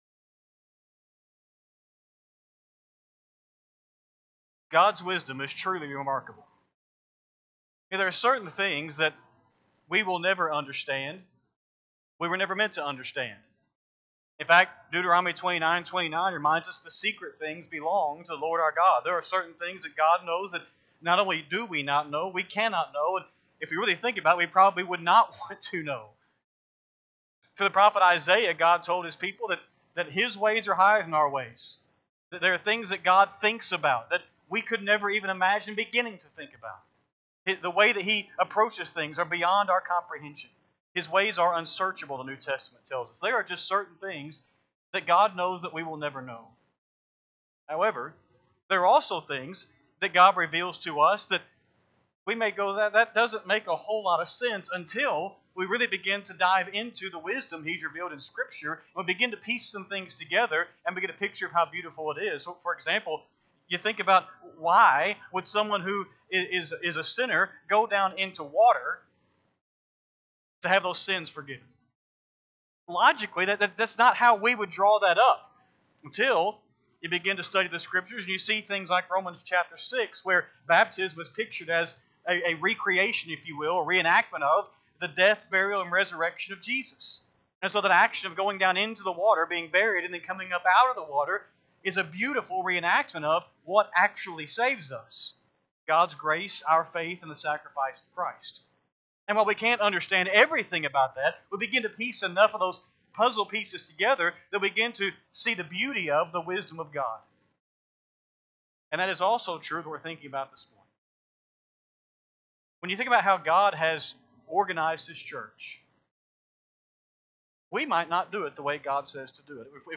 Sunday-AM-Sermon-11-2-25-Audio.mp3